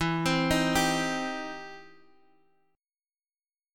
Cm/E chord
C-Minor-E-x,x,2,5,4,3-8.m4a